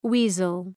Προφορά
{‘wi:zəl}
weasel.mp3